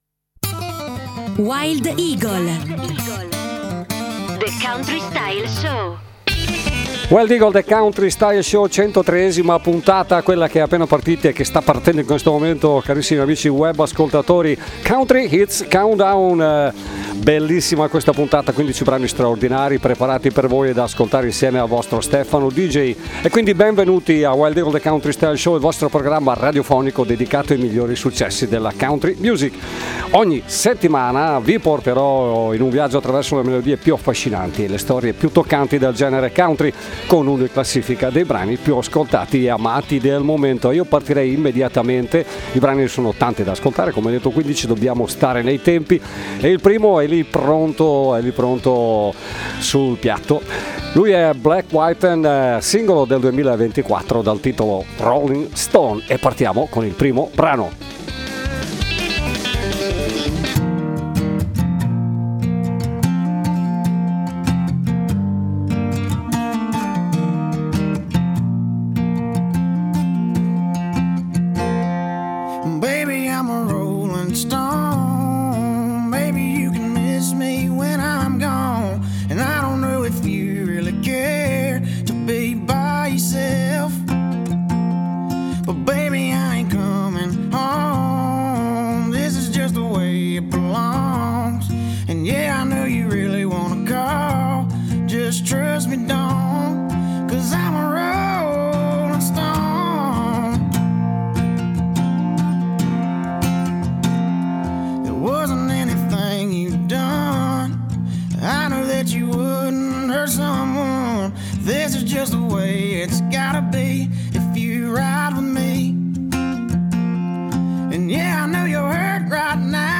Benvenuti a Wild Eagles The Country Style Show, il vostro programma radiofonico dedicato ai migliori successi della musica country!